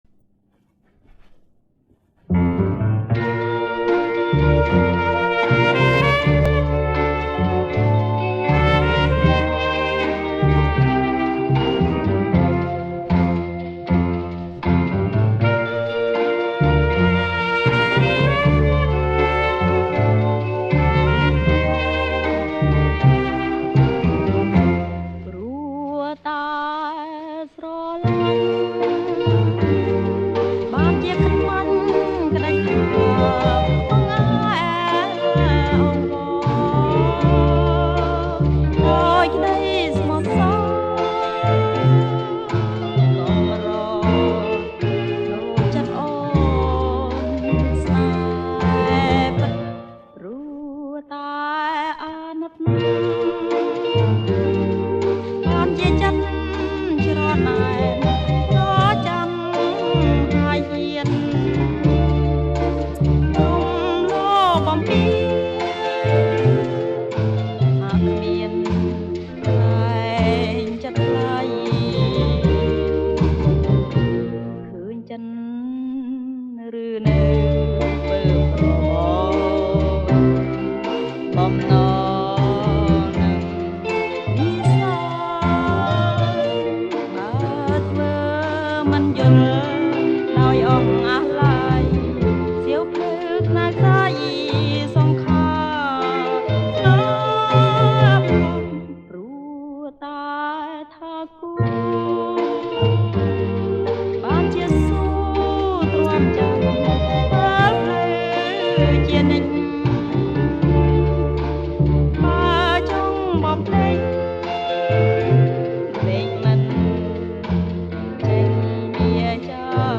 ចេញផ្សាយនៅថាស - Vinyl
• ប្រគំជាចង្វាក់ SLOW ROCK